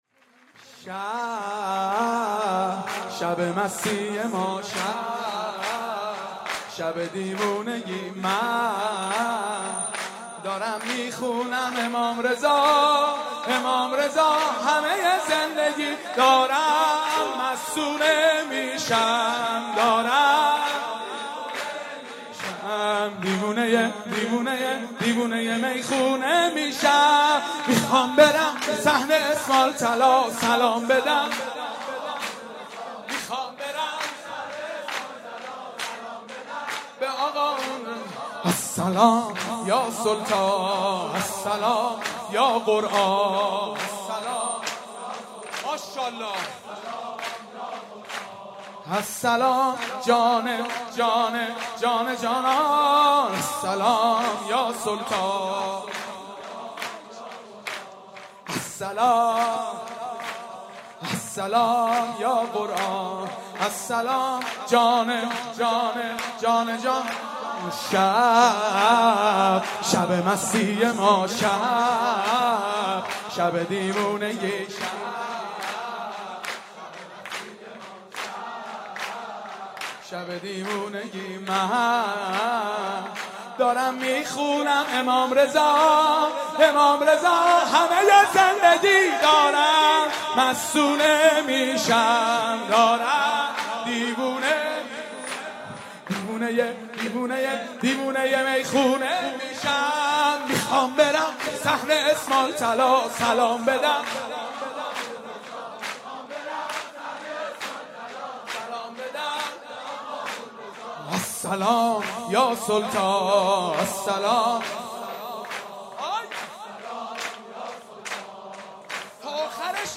جشن میلاد امام رضا با مداحی
سرود
مدح